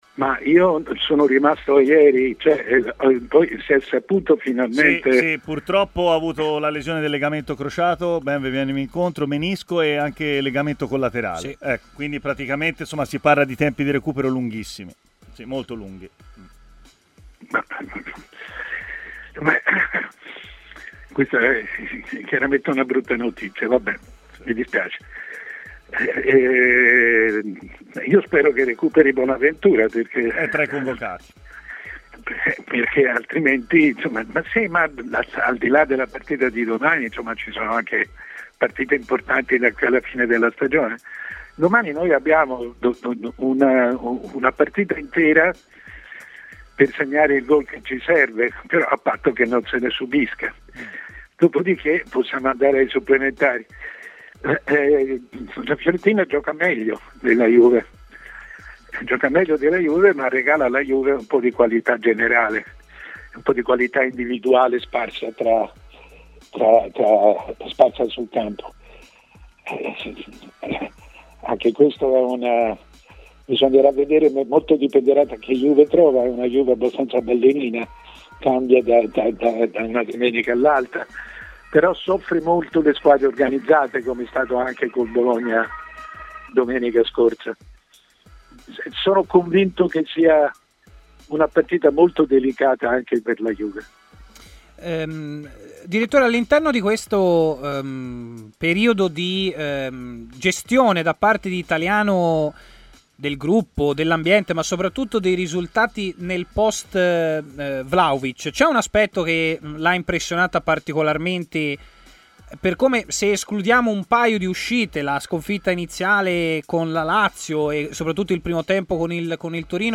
L'opinionista Mario Sconcerti è intervenuto durante Stadio Aperto, trasmissione di TMW Radio, per parlare di vari temi a partire dal passaggio di Milan a Investcorp: "Una cosa estremamente interessante. Il proprietario è dell'Oman, molto ricco, e il fondo è molto serio: negli anni Ottanta ricordo che acquistarono Gucci e si comportarono molto seriamente, pur mettendo alla porta la famiglia. Quando arriva tanta ricchezza, il calcio che la riceve si deve preparare a rispondere: o reagirà mettendo altri soldi, o lascerà strada a loro e andremo incontro a una nuova Juventus dei nove Scudetti".